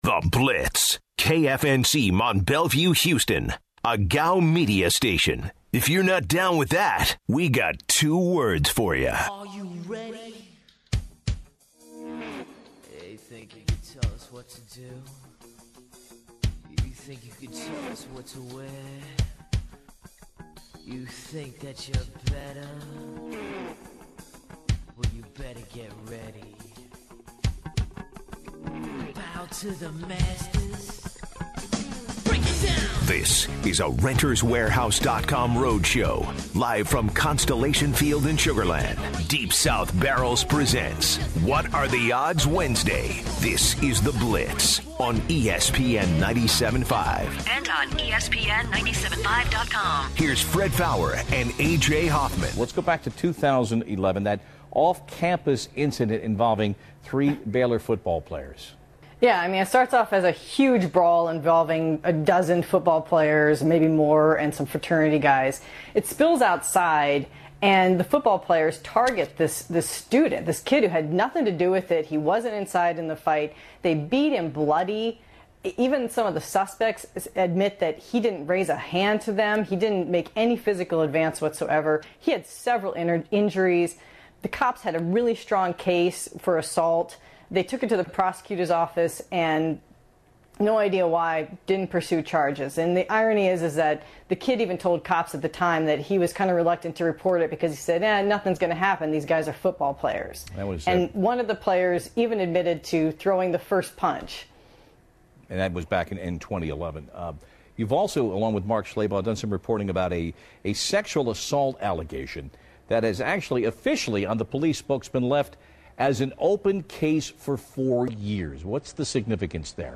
Live from Constellation Field in Sugarland, Texas